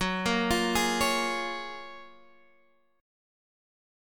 Listen to GbM9 strummed